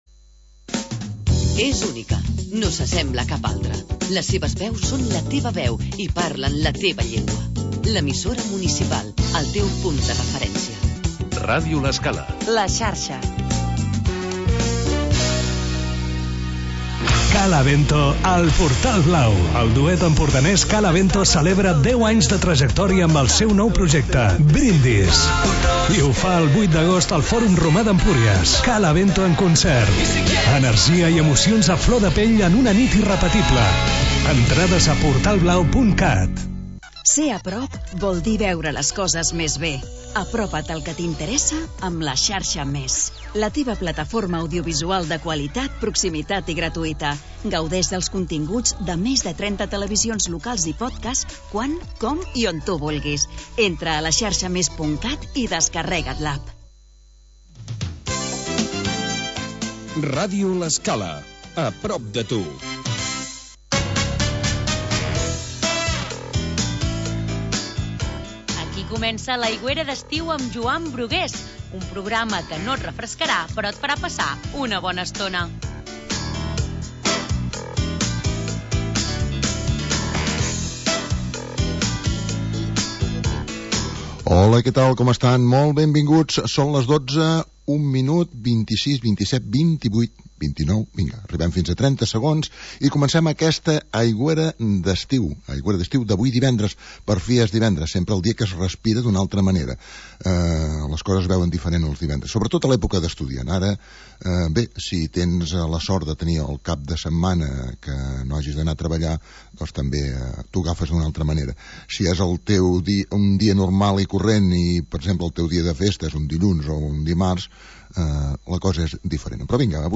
Magazín musical